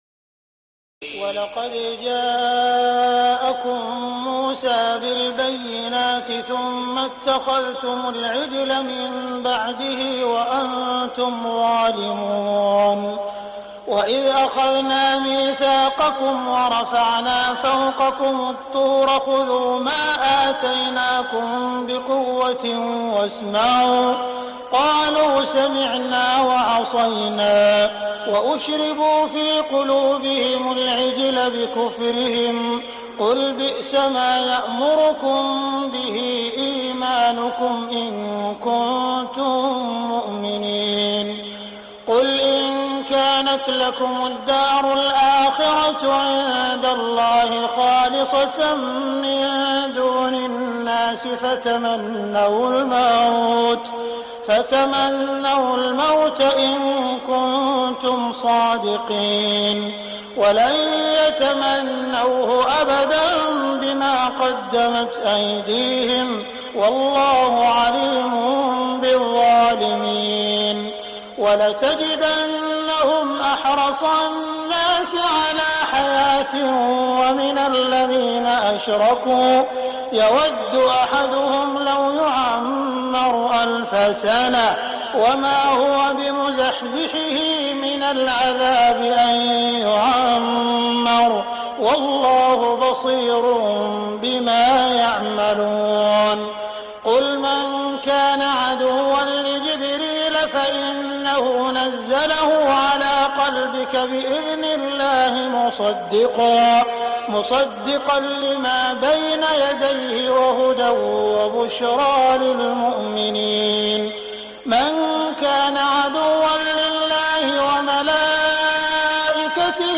تهجد ليلة 21 رمضان 1418هـ من سورة البقرة (92-141) Tahajjud 21 st night Ramadan 1418H from Surah Al-Baqara > تراويح الحرم المكي عام 1418 🕋 > التراويح - تلاوات الحرمين